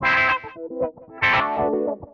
GUITAR LOOPS - PAGE 1 2 3 4
CLEAN FILTER (185Kb)